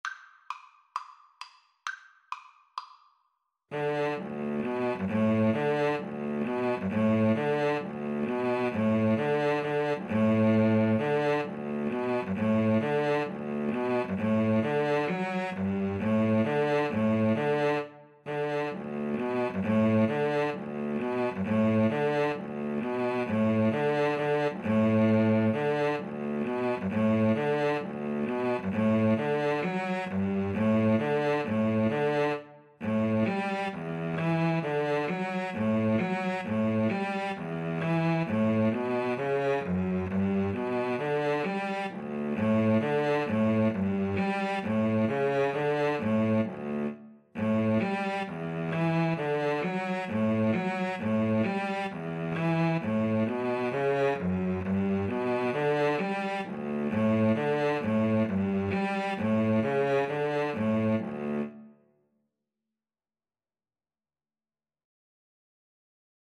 D major (Sounding Pitch) (View more D major Music for Cello Duet )
=132 Molto allegro
Traditional (View more Traditional Cello Duet Music)